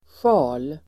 Ladda ner uttalet
Uttal: [sja:l]